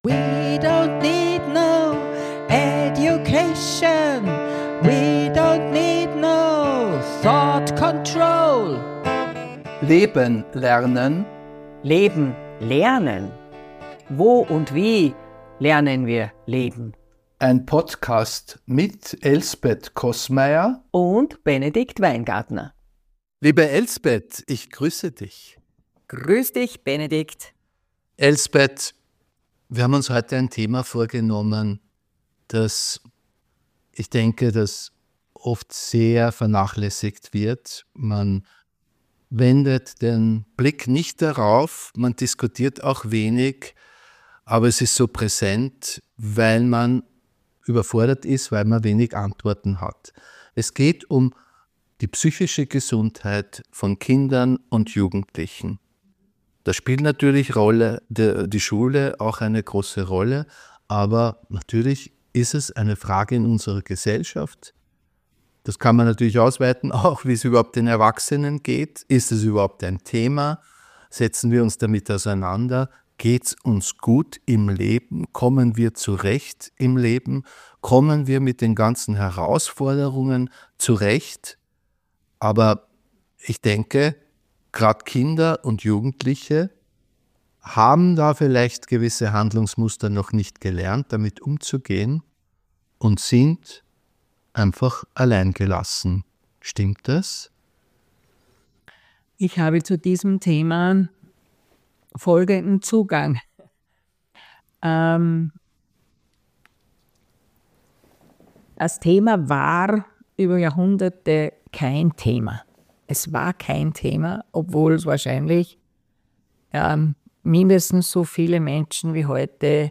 Mit ihren unterschiedlichen Zugängen führen sie kritisch und mit utopischen Gedanken im Hinterkopf einen Dialog.